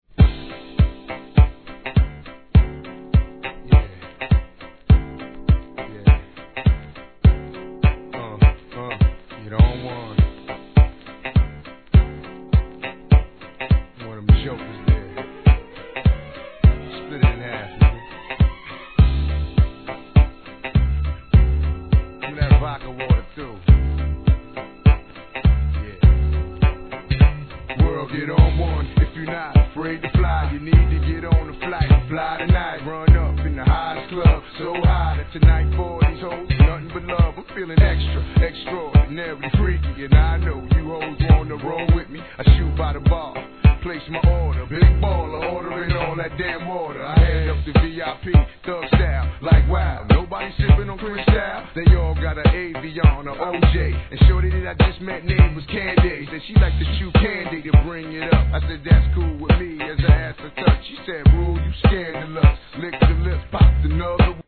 HIP HOP/R&B
程よいテンポで刻むBEATとギターのリフが気持ちよく乗れるスムース・ナンバー!